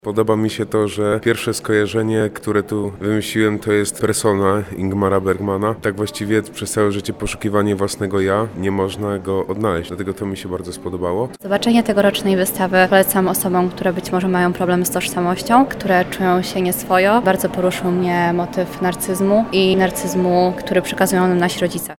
Tym razem wybranym motywem mitologicznym był Narcyz. Posłuchajcie relacji uczestników.